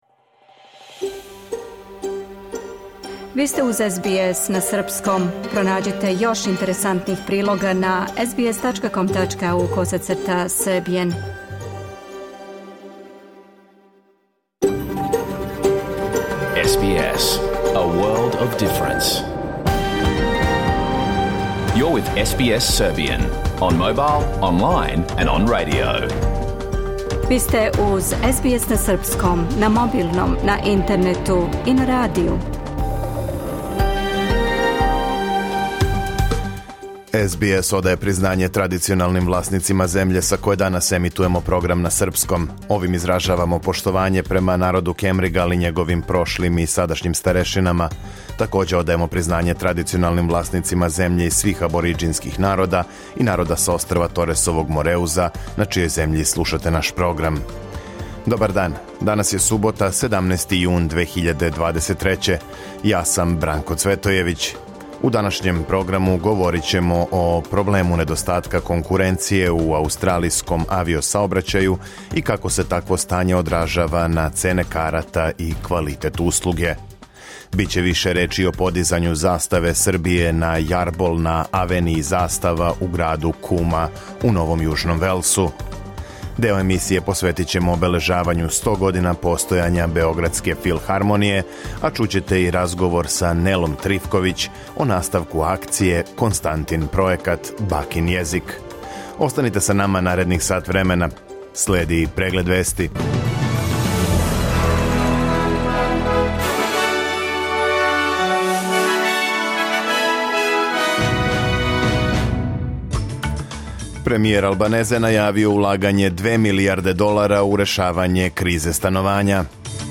Програм емитован уживо 17. јуна 2023. године